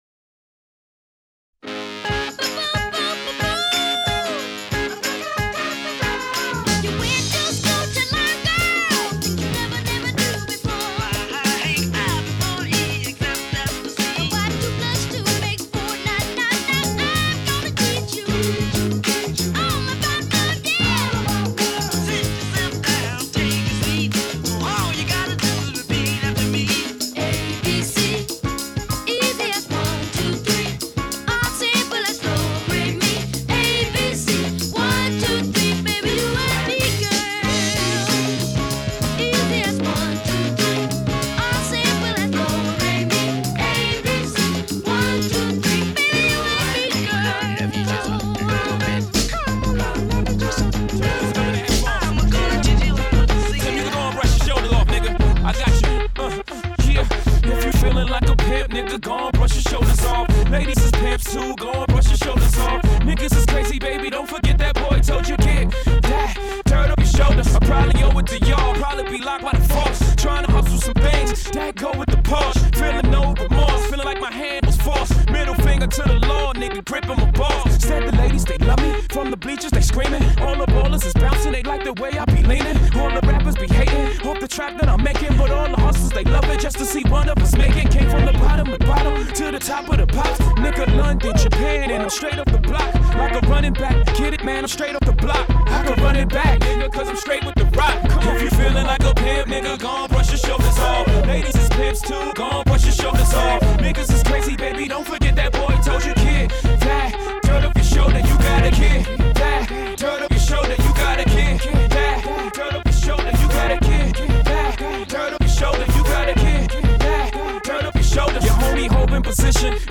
Dance Mix https